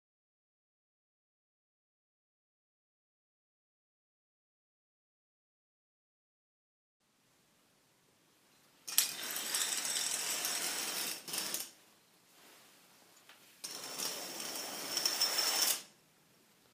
描述：打开/关闭窗帘